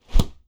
Close Combat Sounds Effects / Regular Attack
Close Combat Attack Sound 17.wav